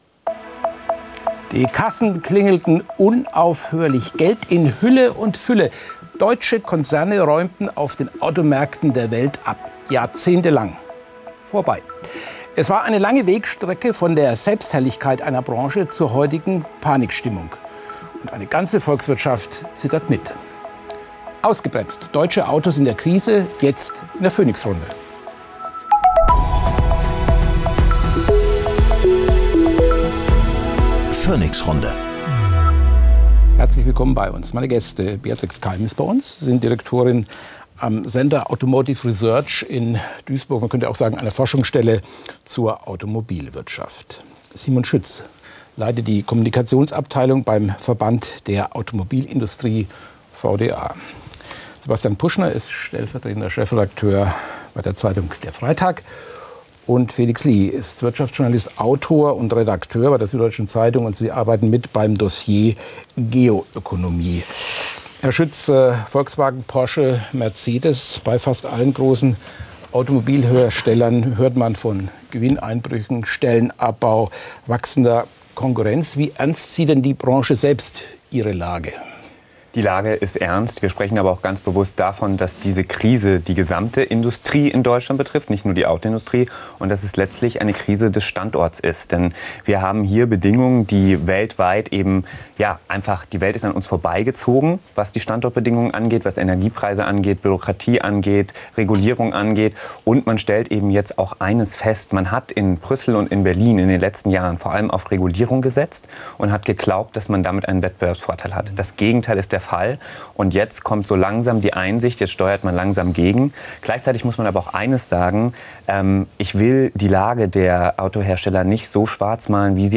diskutiert mit seinen Gästen